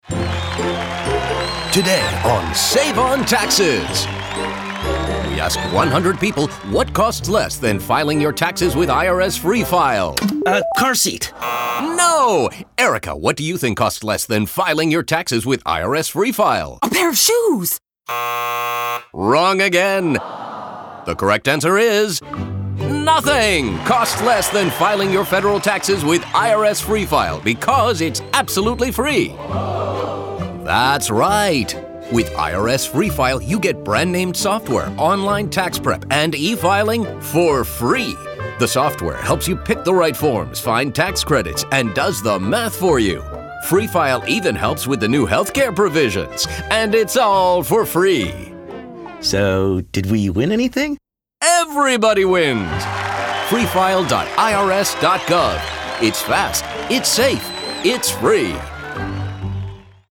2016-11-30 00:00:00 2.29 MB IRS Free File - Game Show, The Next Round - Radio PSA :60